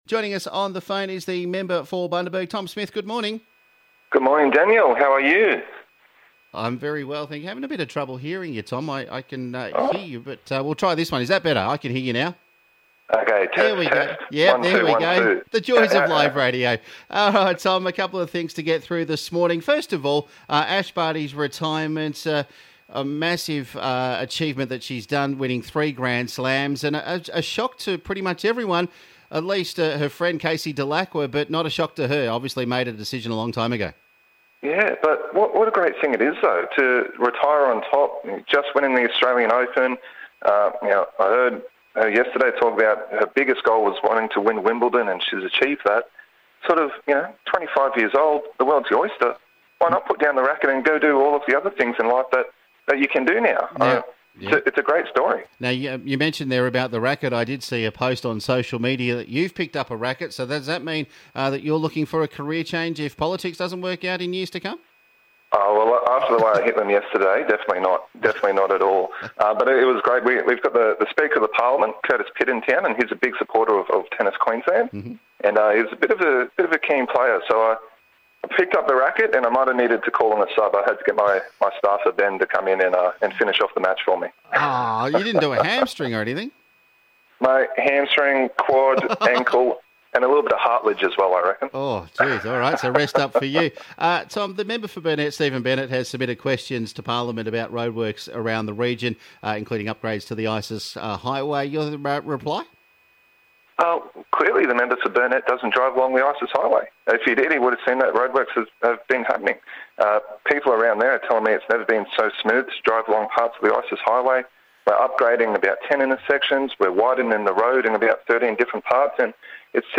Member for Bundaberg Tom Smith joined The Big Brekky to talk about the retirement of Ash Barty, roadworks around the region and vaccination update for the region